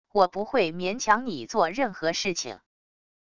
我不会勉强你做任何事情wav音频生成系统WAV Audio Player